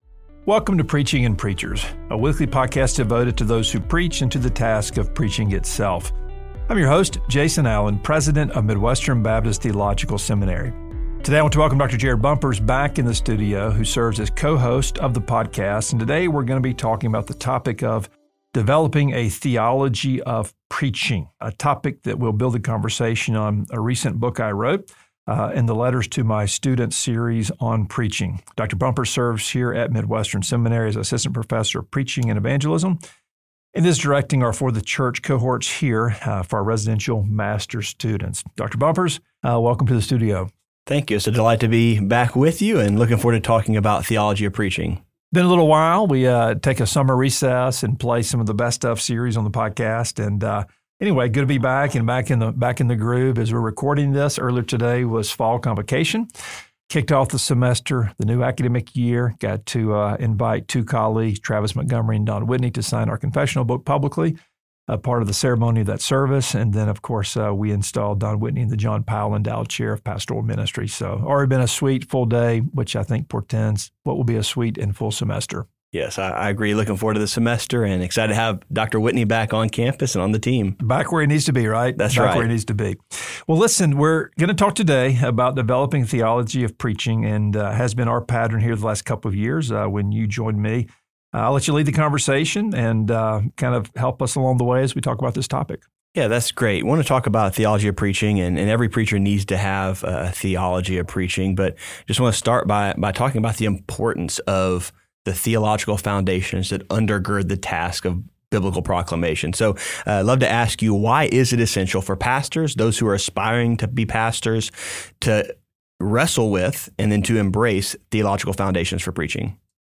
back in the studio as co-host